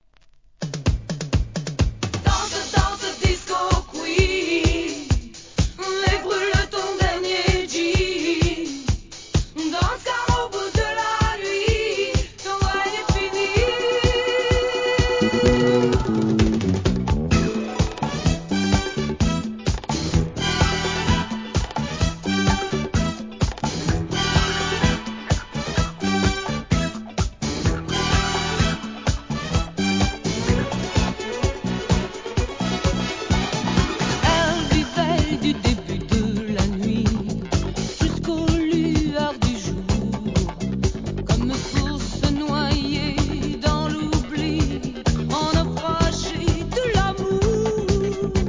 ¥ 385 税込 関連カテゴリ SOUL/FUNK/etc...